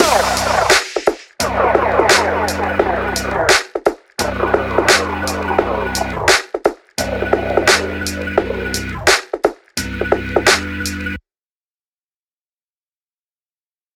EMO HIPHOP LOOP TRACK BPM86 Em/Gmaj OUT